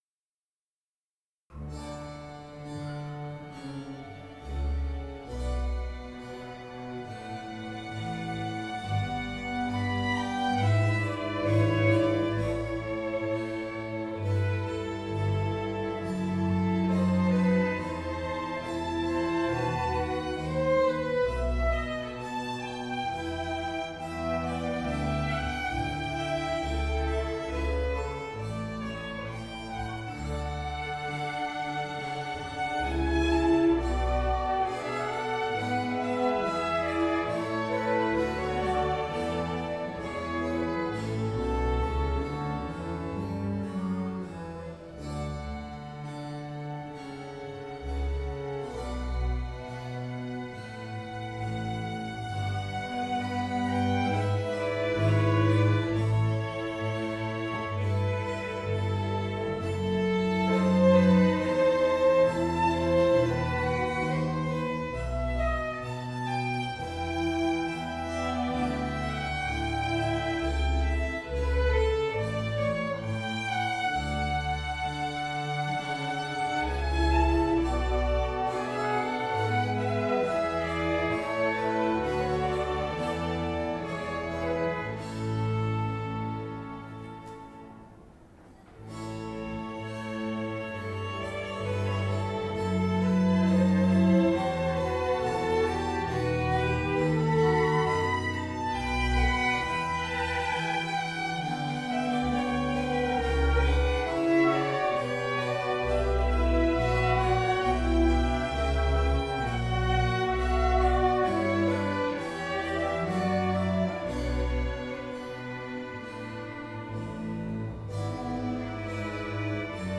Aria dalla terza suite per orchestra di Johann Sebastian Bach
Quartetto dell'Ensemble Concentus Venetiae
Dal Concerto del 16 ottobre 2016